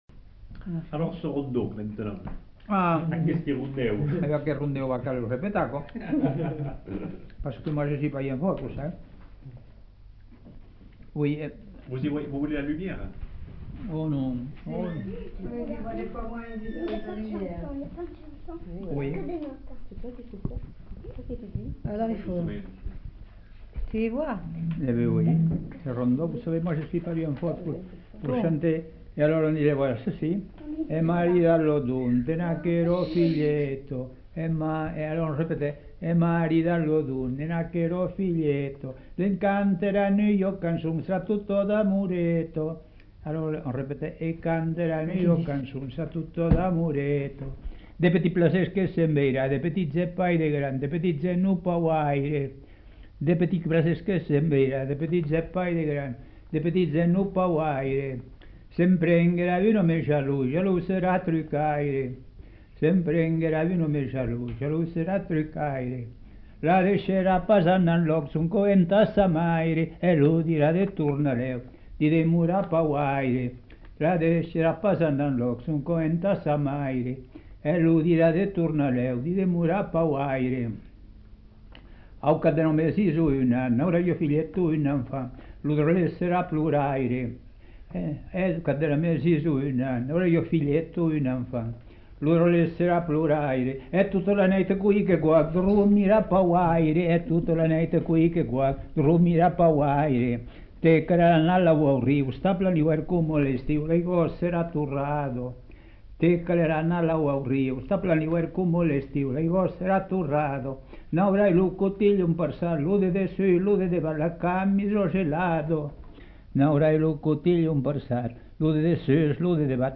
Lieu : Montaut
Genre : chant
Effectif : 1
Type de voix : voix d'homme
Production du son : chanté